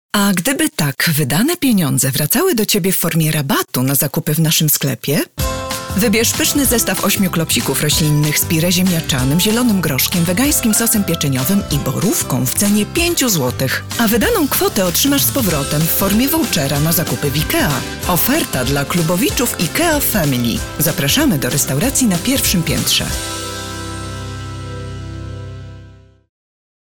Female 30-50 lat
Soft, warm voice with great interpretive and narrative abilities.
Demo lektorskie
Spot reklamowy